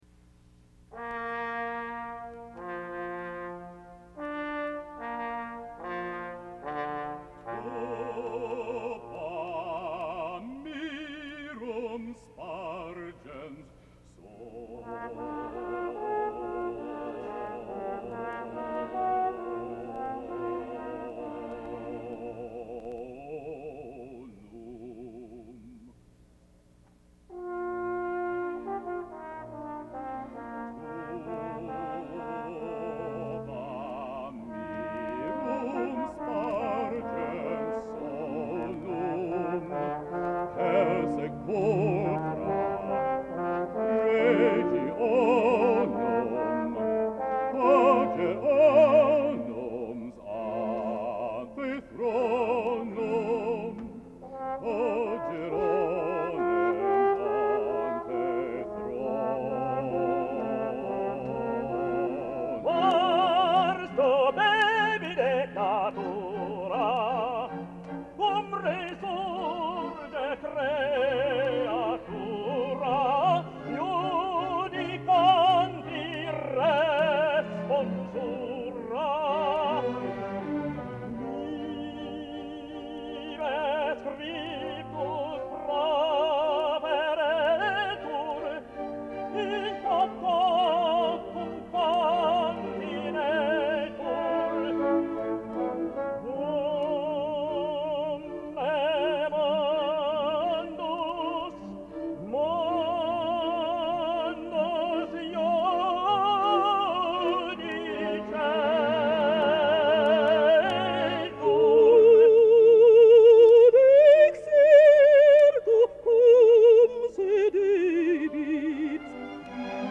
Below are files from the actual performance at the National Cathedral!